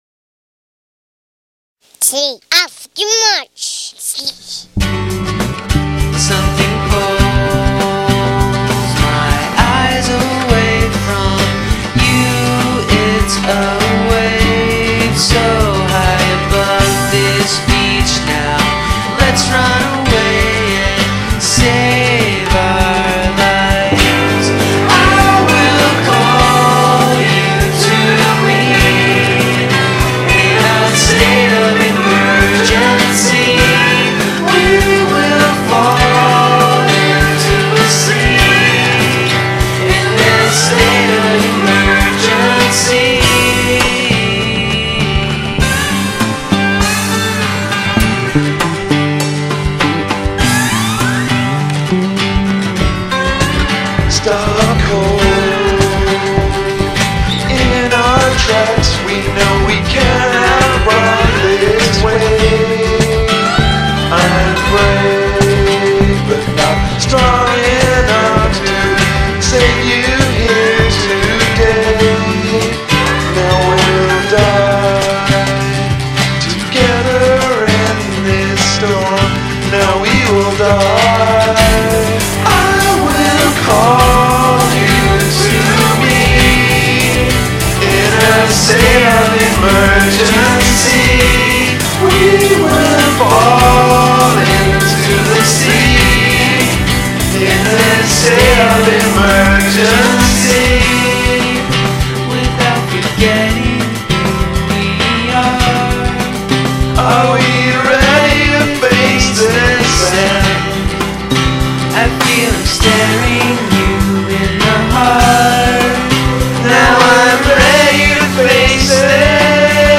Guest Vocals